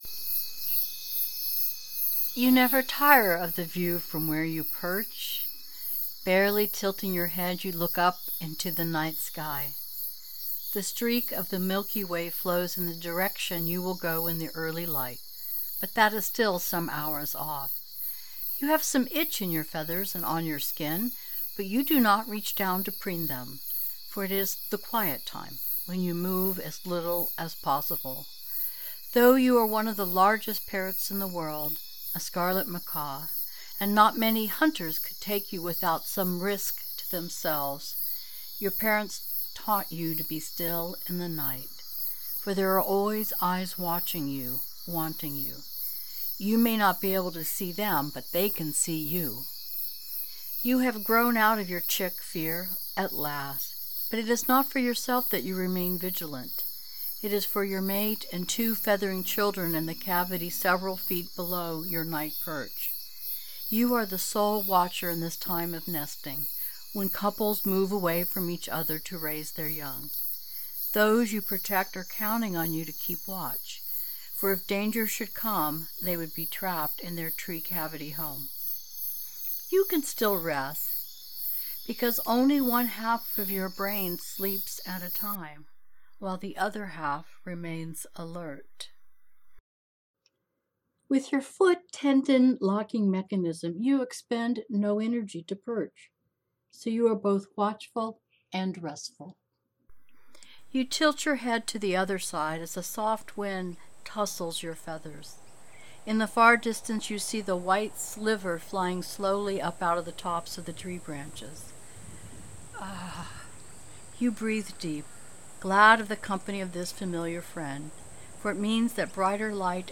Exercises to put yourself into the feathers of another include guided meditations, and One Earth has just produced our first one.